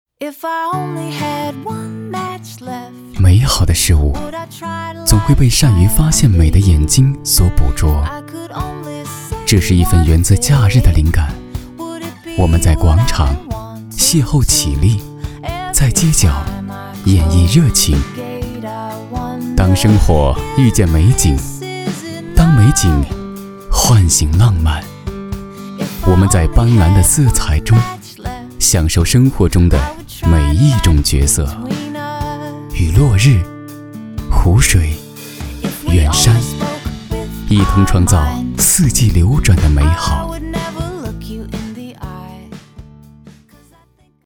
男国184年轻活力时尚配音-新声库配音网
男国184_广告_地产_房地产广告_温情.mp3